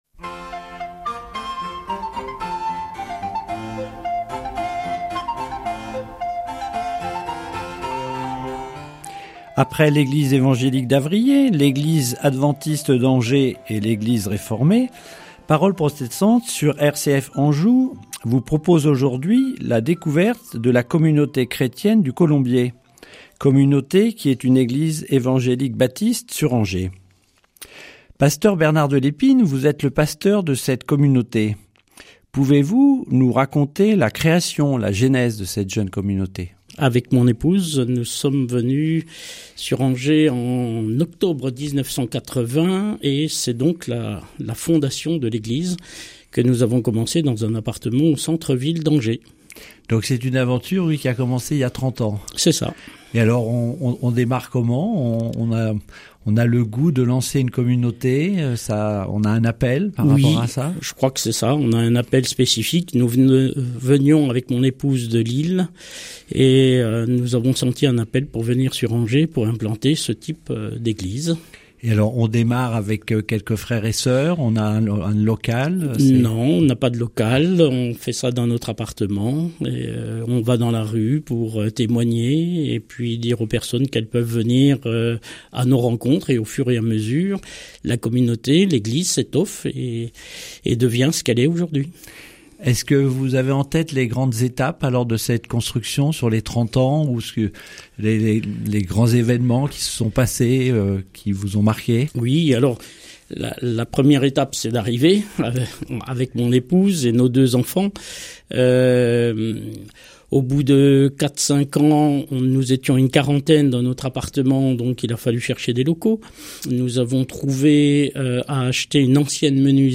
Séries : Emission de radio RCF | Très nombreuses sont les différentes églises protestantes et certaines sont implantées en Maine-et-Loire.